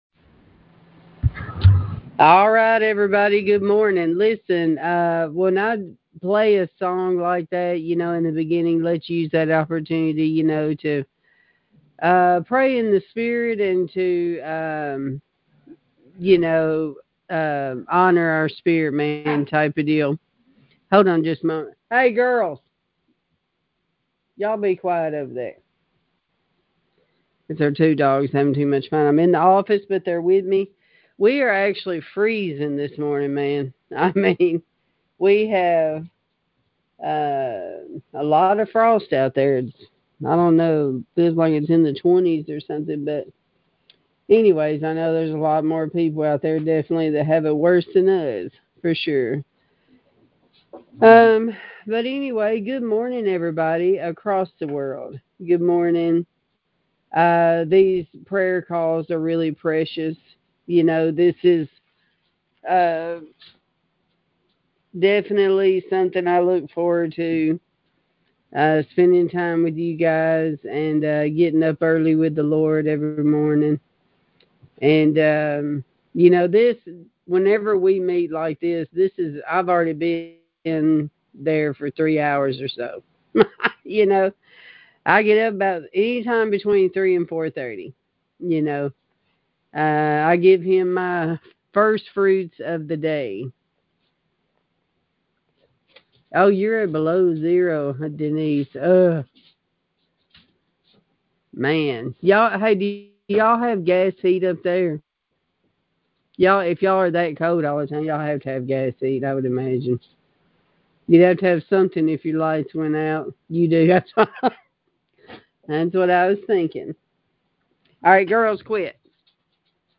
Sermons | Garden of Eden Ministries